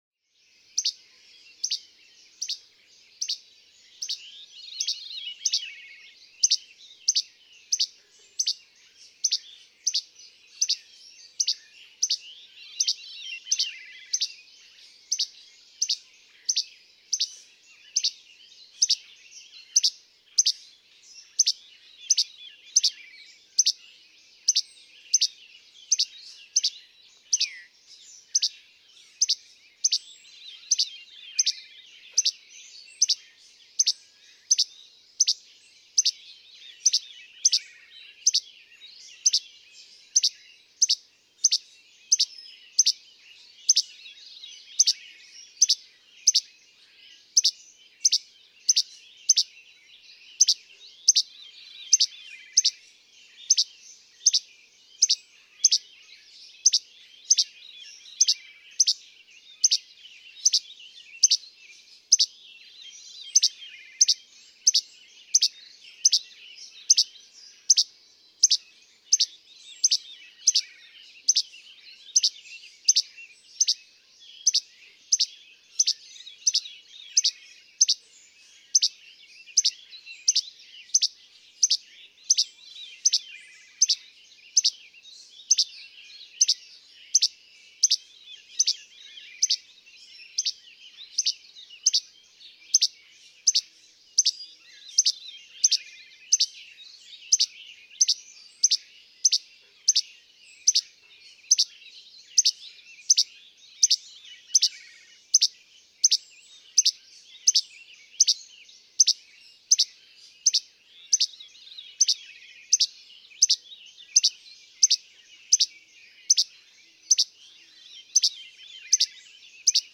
Norwottuck Rail Trail, Amherst, Massachusetts.
634_Least_Flycatcher.mp3